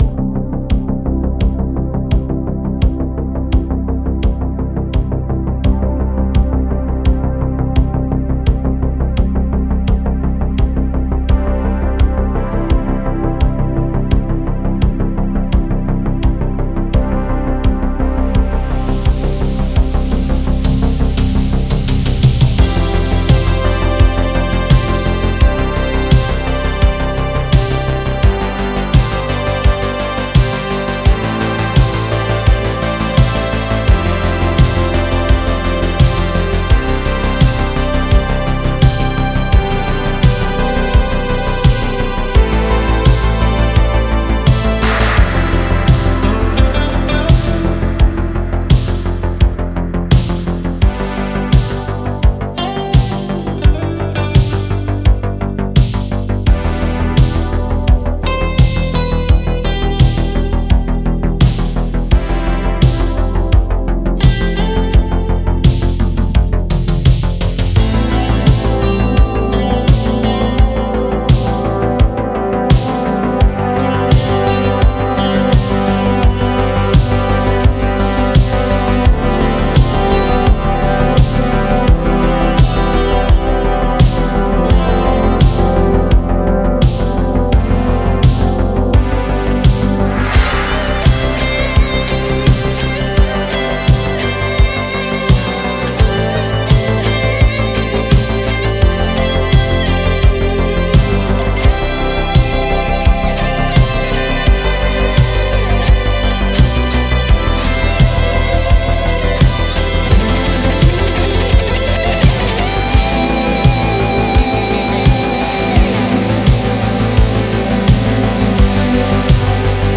Стиль: Chillout/Lounge / Synthwave